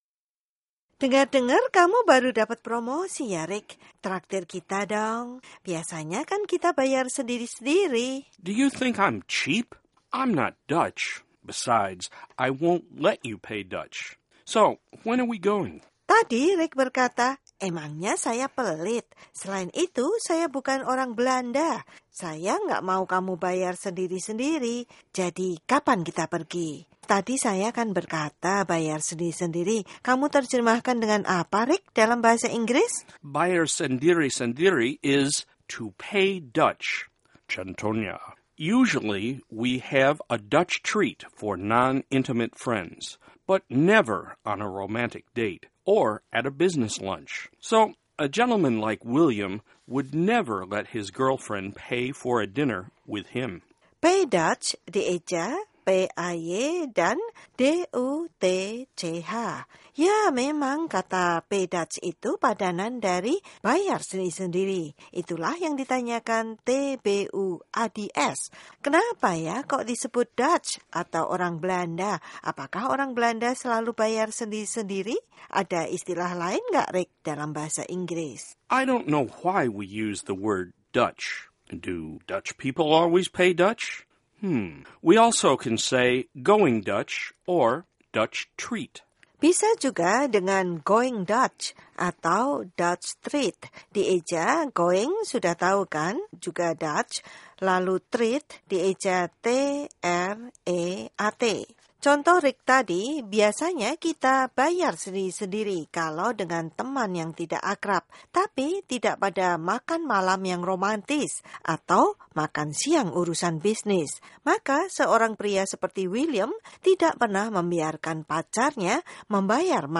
Pelajaran bahasa Inggris membahas pertanyaan pendengar VOA, baik dari bahasa Inggris ke Indonesia dan sebaliknya.